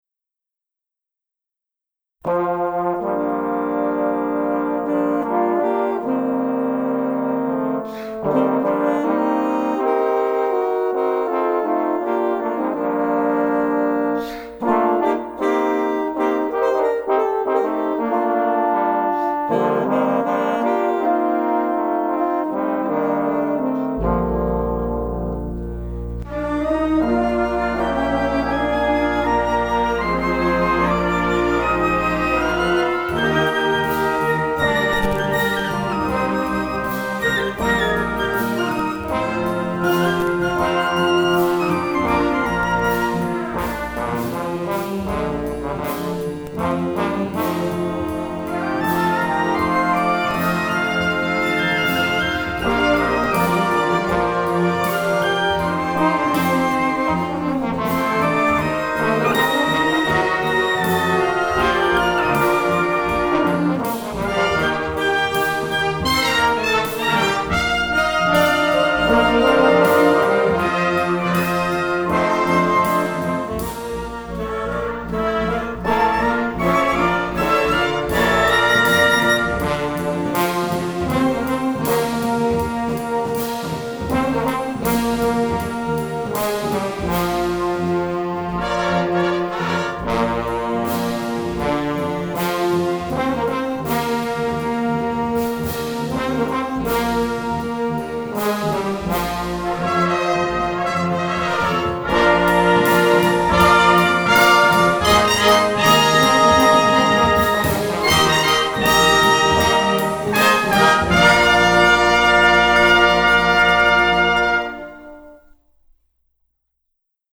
Band members recorded their parts individually.